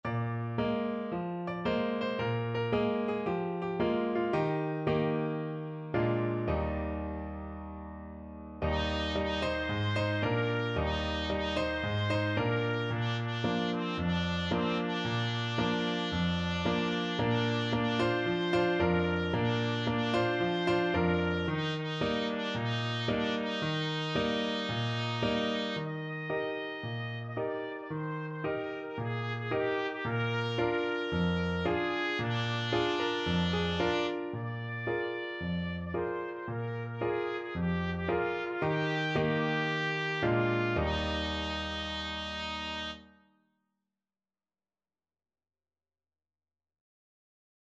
C5-Eb6
4/4 (View more 4/4 Music)
Cheerfully! =c.112
Traditional (View more Traditional Trumpet Music)